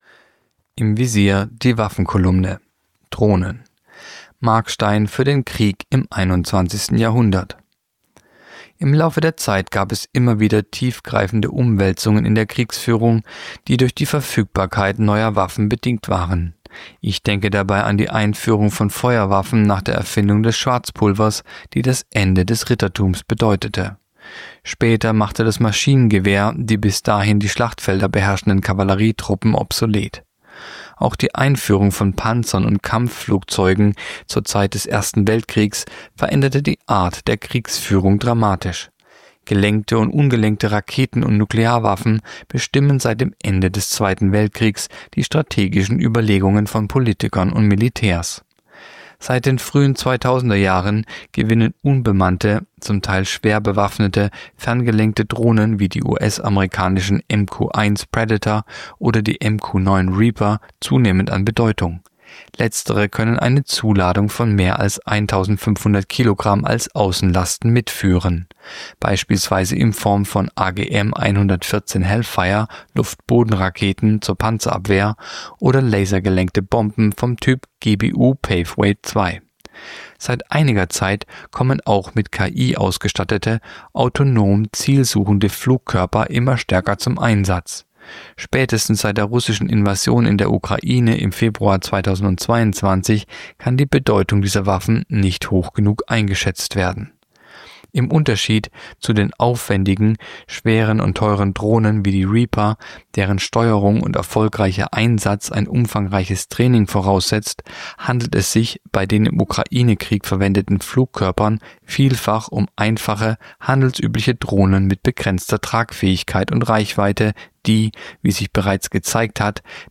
Der erfolgreiche Artikel „Drohnen“